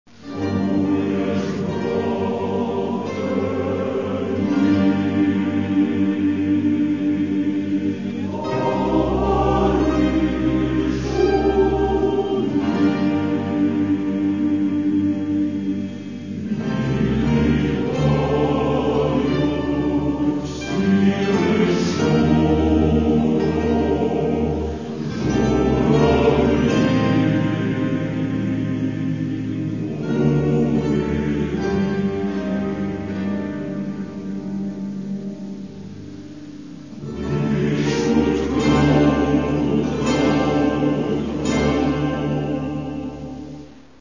Catalogue -> Folk -> Bandura, Kobza etc
lyrics: ukrainian folk song